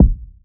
• Urban Kick Drum One Shot C Key 71.wav
Royality free steel kick drum sound tuned to the C note. Loudest frequency: 136Hz
urban-kick-drum-one-shot-c-key-71-vQs.wav